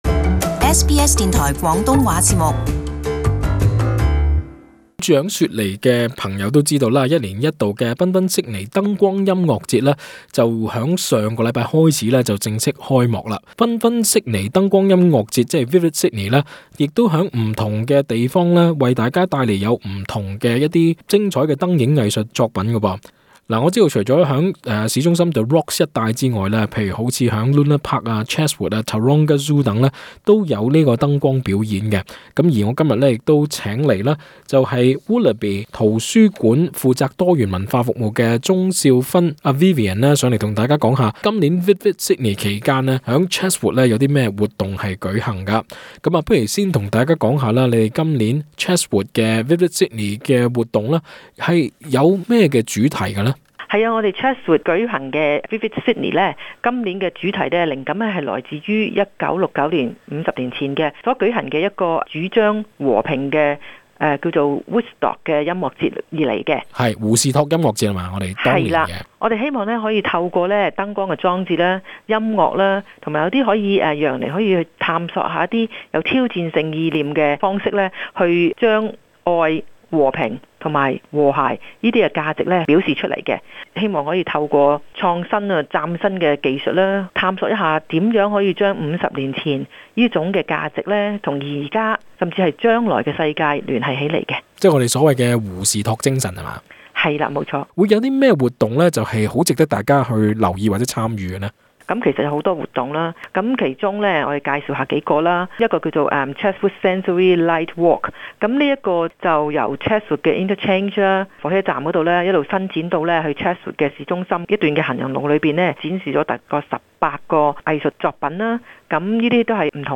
【社區專訪】新州Chatswood今年於Vivid Sydney再放異彩